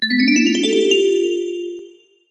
snsの通知音に最適な長さのサウンド。